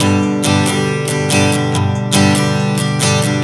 Strum 140 G 01.wav